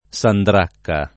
sandracca [ S andr # kka ]